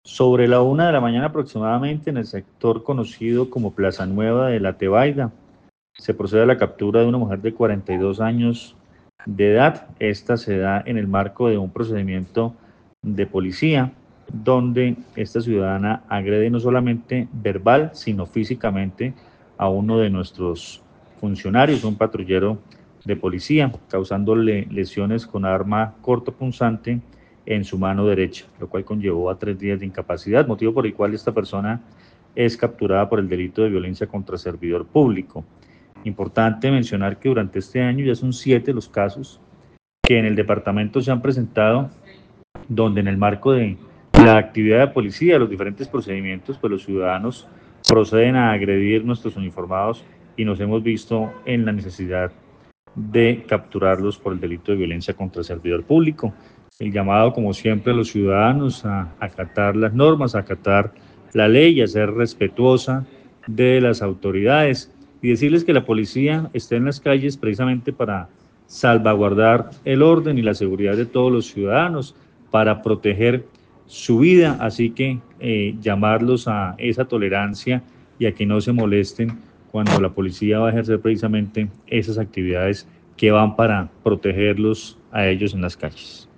Al respecto el coronel Luis Fernando Atuesta, comandante de la Policía del departamento informó que la mujer fue capturada y deberá responder por el delito de violencia contra servidor público.